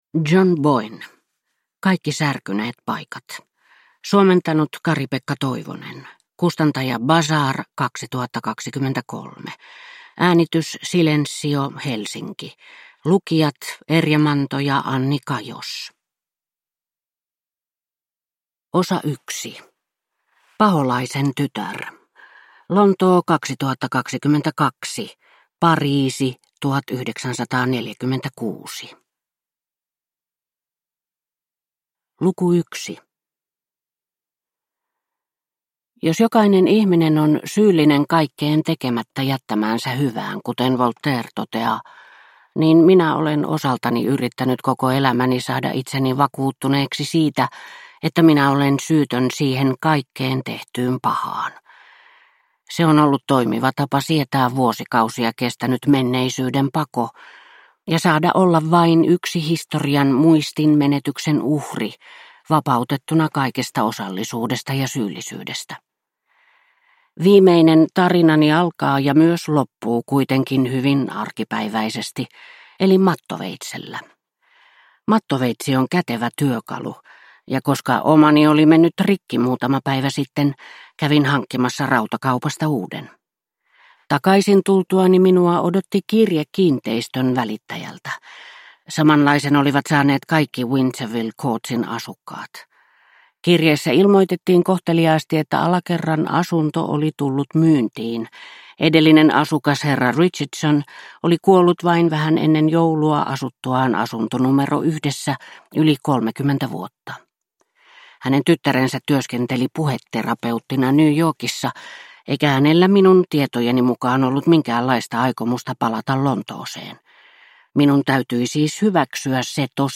Kaikki särkyneet paikat – Ljudbok – Laddas ner